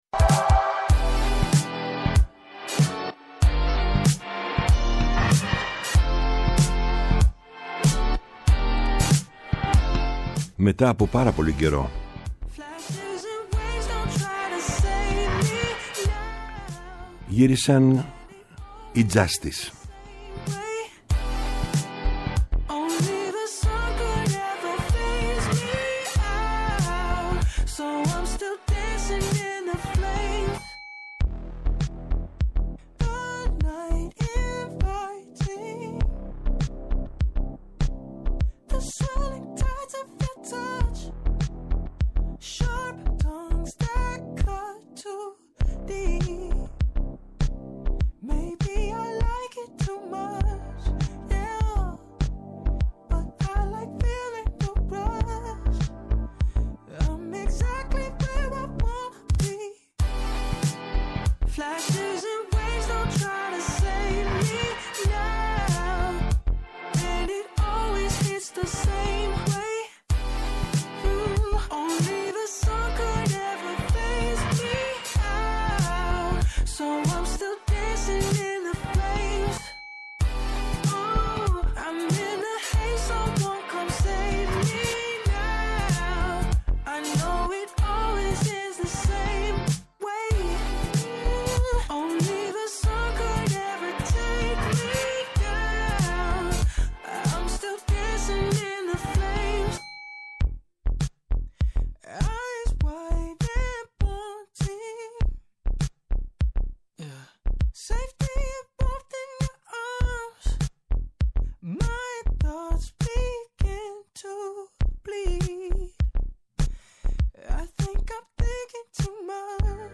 Η μακροβιότερη εκπομπή στο Ελληνικό Ραδιόφωνο!